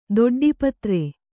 ♪ doḍḍi patre